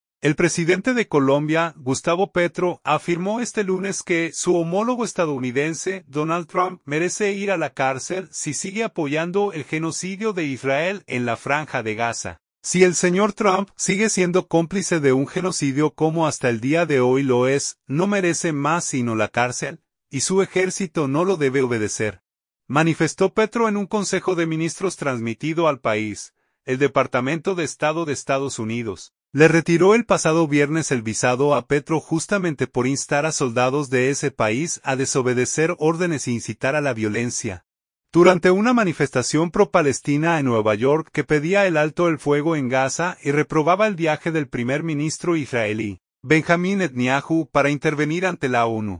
Y su ejército no lo debe obedecer», manifestó Petro en un consejo de ministros transmitido al país.